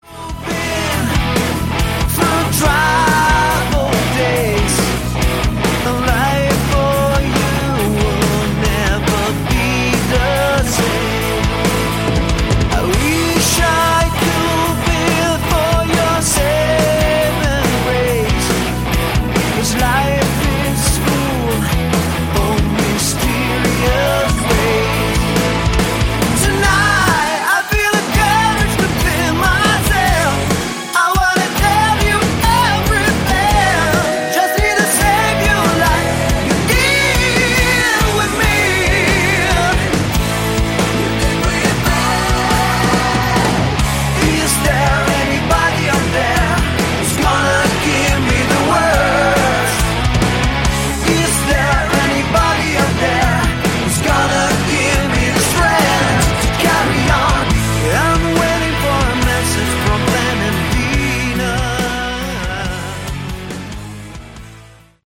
Category: Melodic Hard Rock
lead and backing vocals
rhythm guitars, lead and acoustic guitars
lead guitars, guitar solos
bass
drums